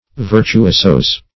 Virtuoso \Vir`tu*o"so\, n.; pl. Virtuosos; It. Virtuosi.